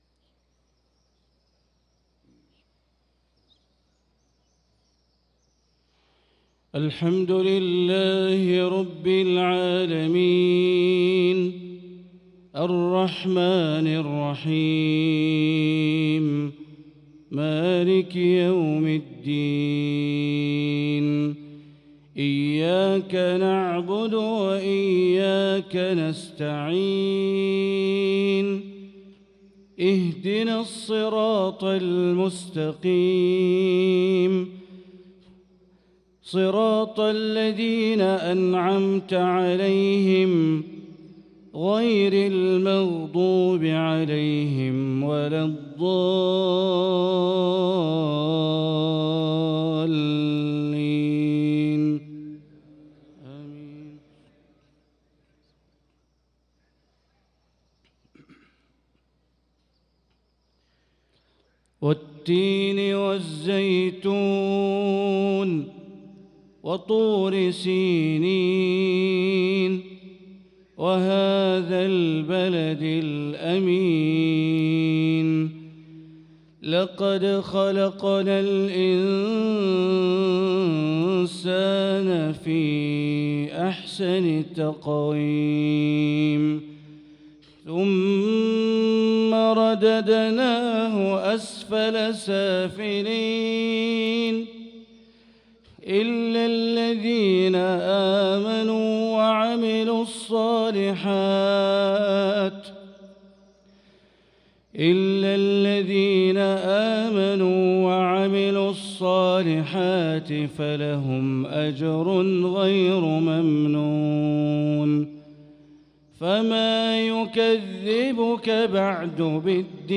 صلاة المغرب للقارئ بندر بليلة 25 رجب 1445 هـ